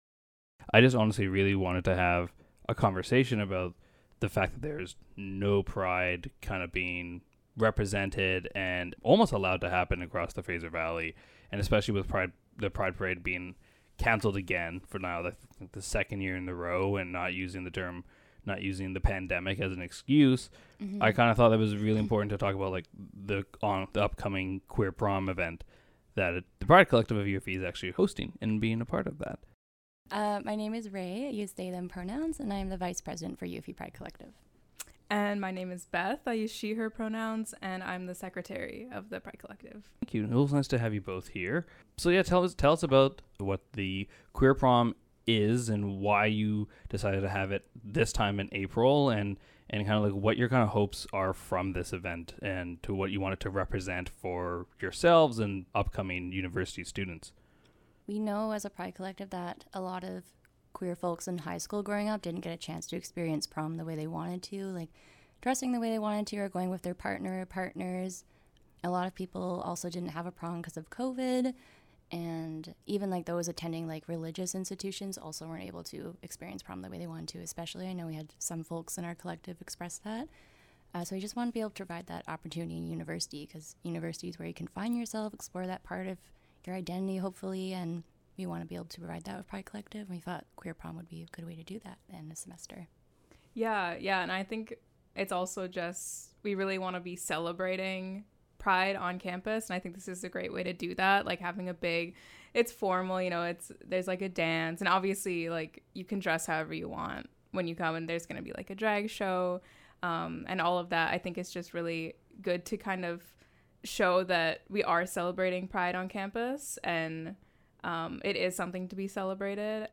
LJI-1.1-Queer-Prom-Interview-long.mp3